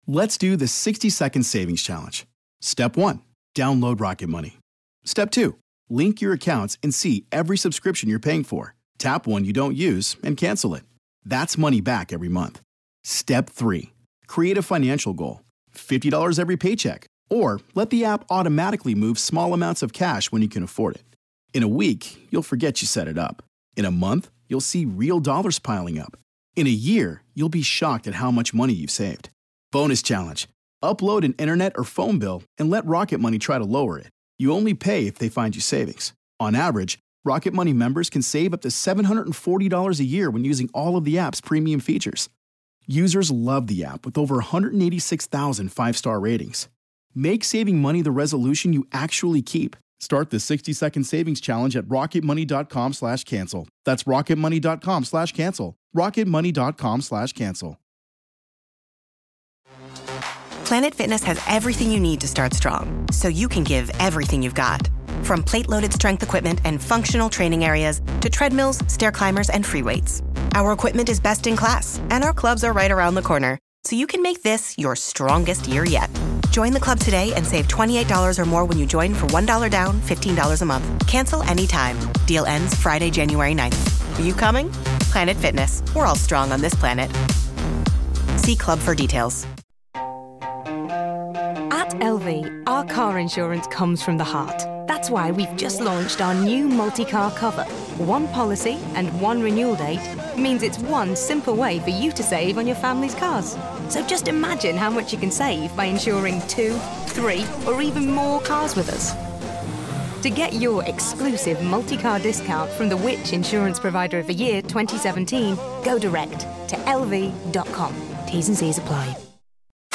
E3 2016: Forza Horizon 3 Interview